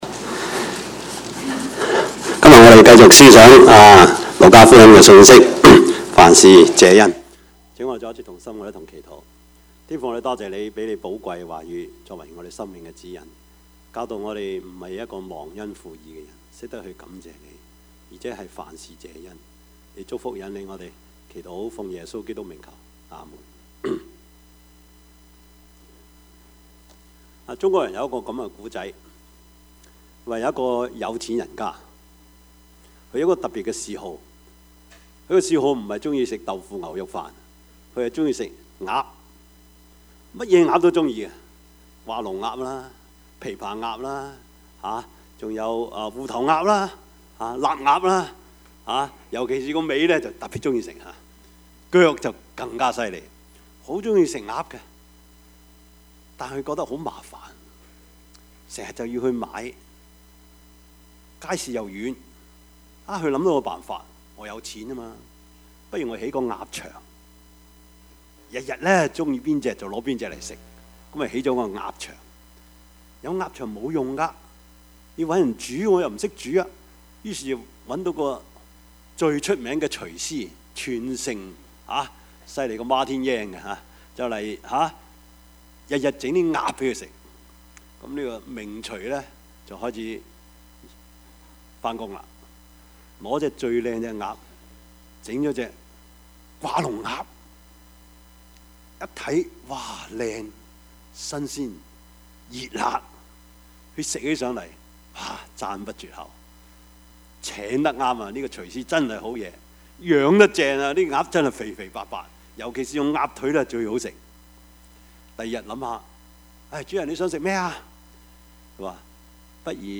Service Type: 主日崇拜
Topics: 主日證道 « 生兒養女 (福音主日) 女人唔易做 »